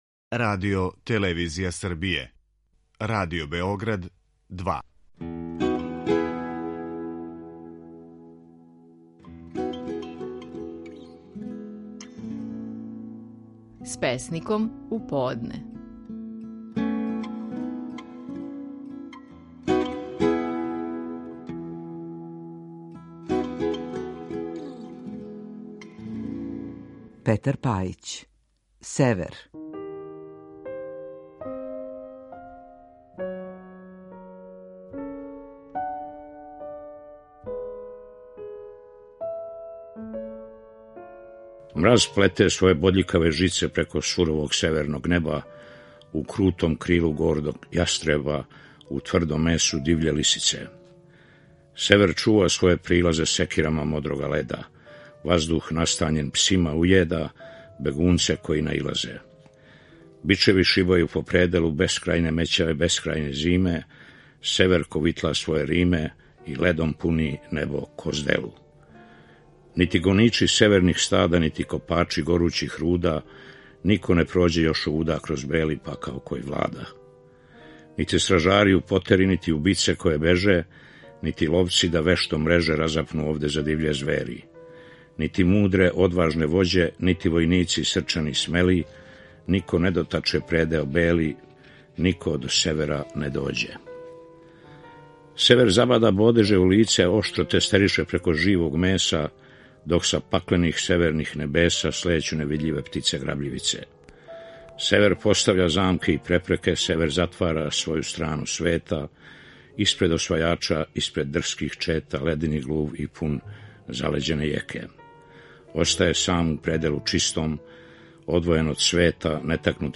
Стихови наших најпознатијих песника, у интерпретацији аутора.
Петар Пајић казује своју песму „Север".